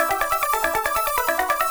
Index of /musicradar/8-bit-bonanza-samples/FM Arp Loops
CS_FMArp A_140-E.wav